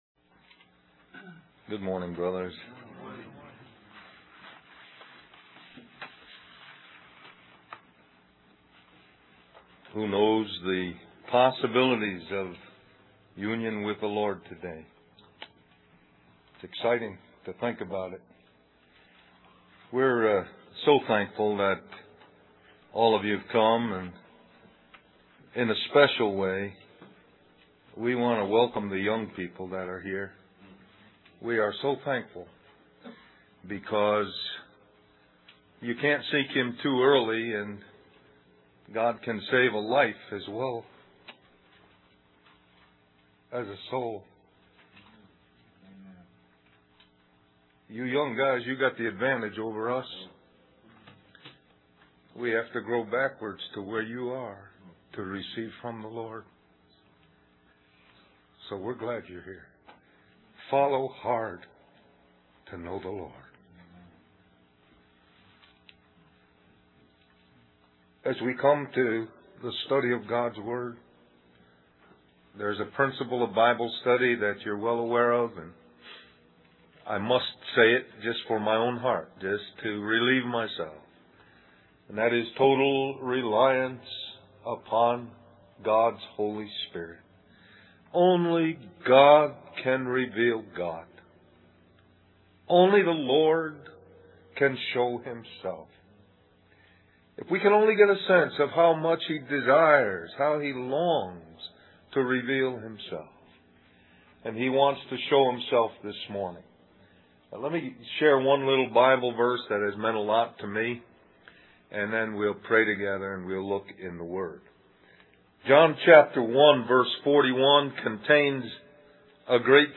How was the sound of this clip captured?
2003 Del-Mar-Va Men's Retreat Stream or download mp3 Summary The Lord speaks to the remnant and tells them that they are doubting the love of God. He shows how this doubt leads to a series of other problems.